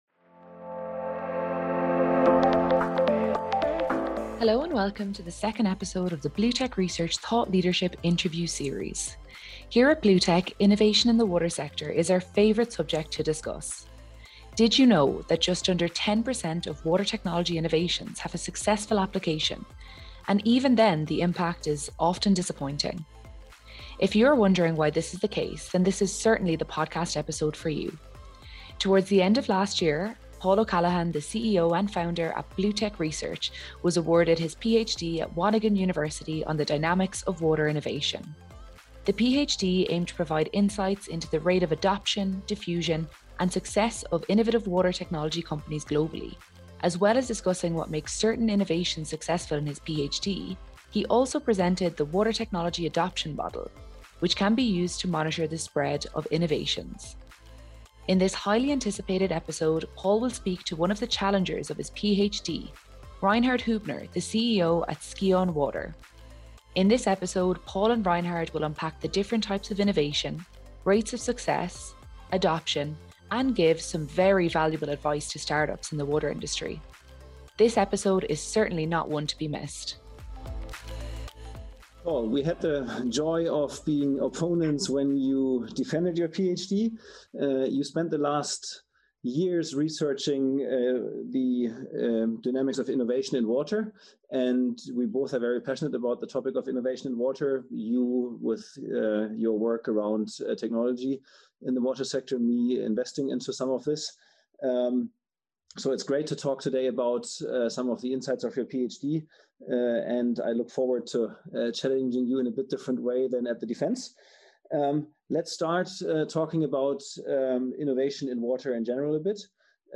Dynamics of Water Innovation: A Conversation